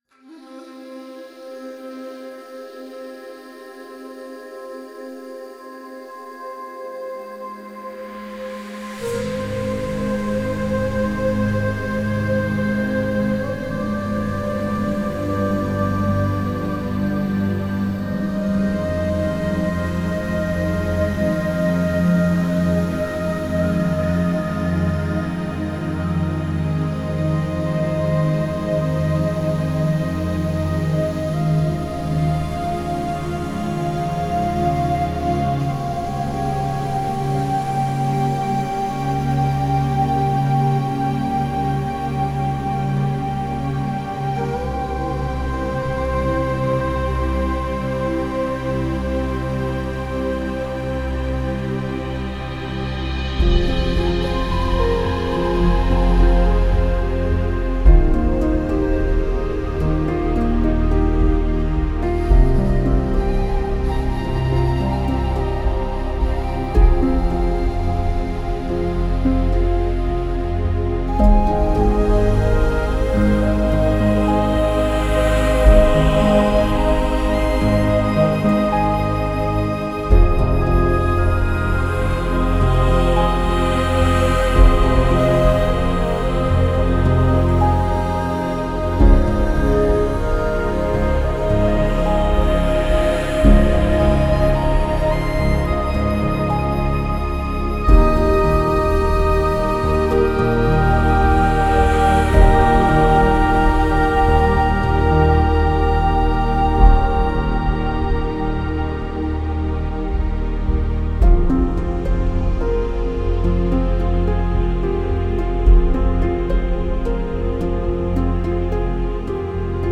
orchestra meditation music
royalty free meditation music tracks